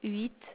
The results are obtained on a database of telephone speech quality.
source sound
Tech. description: 8khz, 16 bit mono adpcm